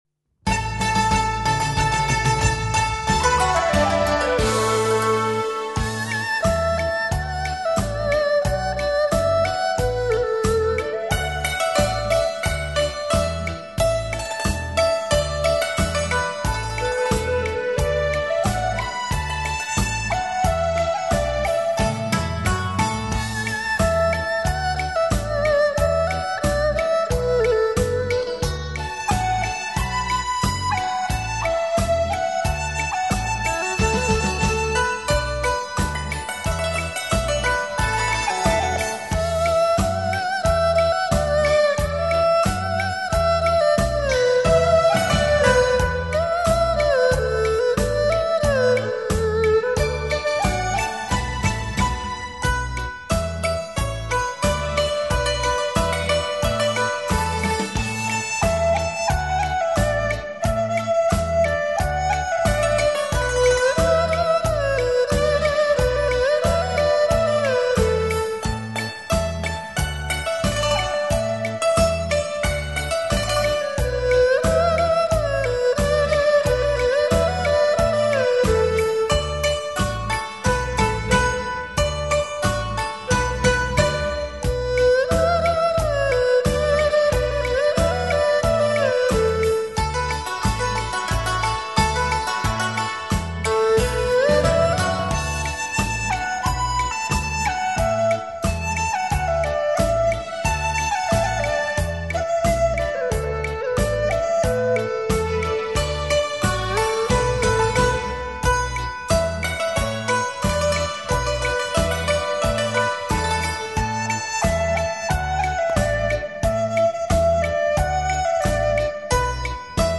吉特巴